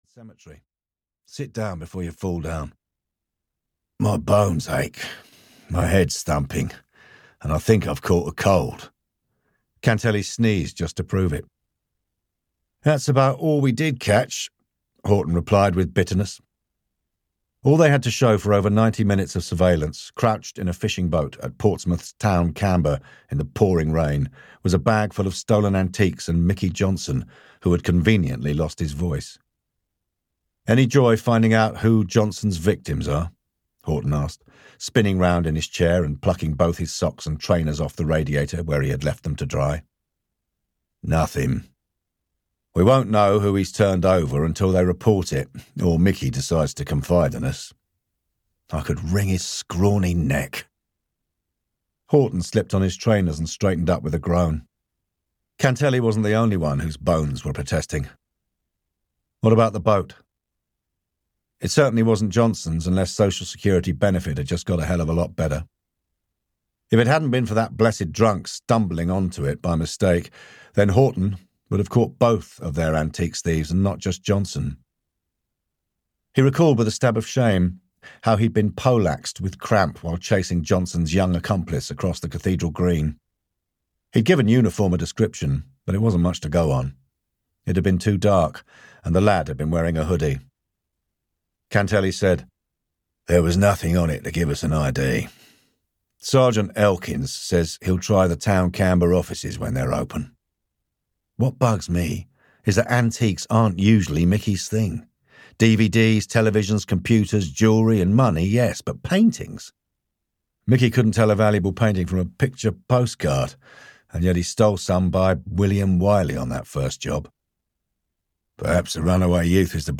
Audio knihaThe Langstone Harbour Murders (EN)
Ukázka z knihy